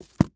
ES_Book Paperback 11 - SFX Producer.wav